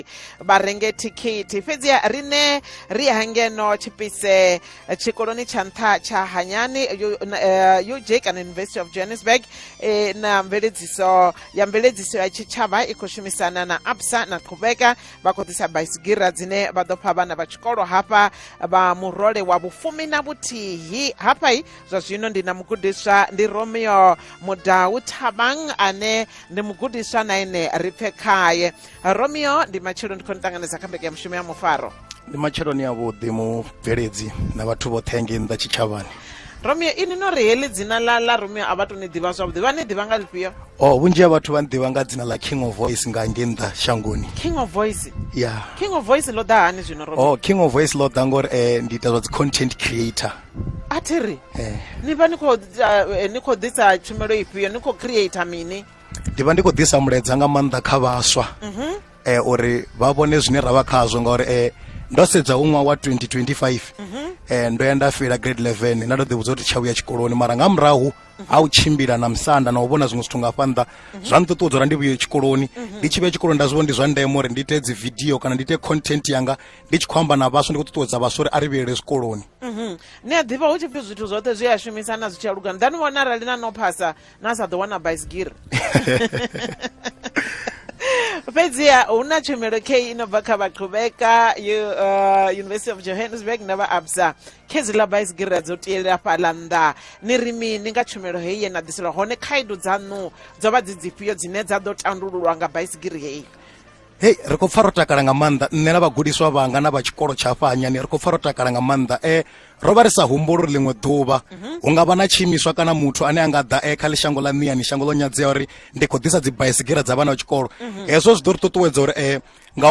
5 Sep Interview on PhalaphalaFM - Part 5